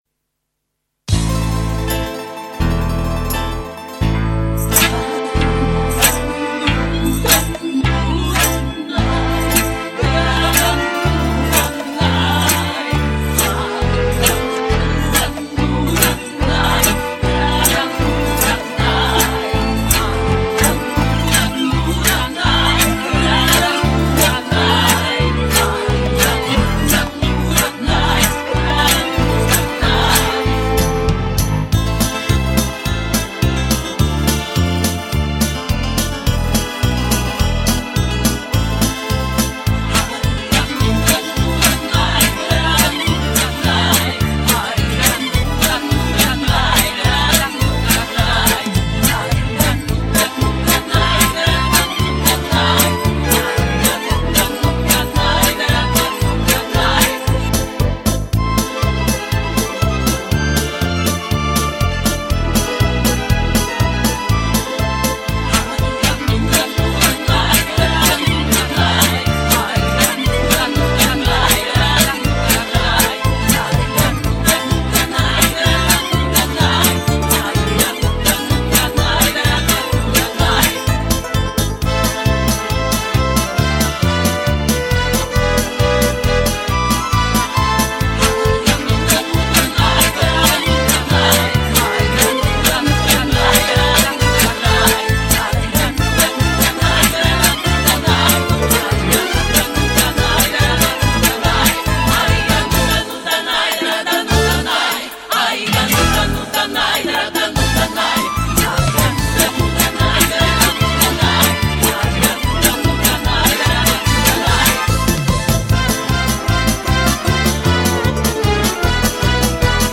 зажигательная цыганская плясовая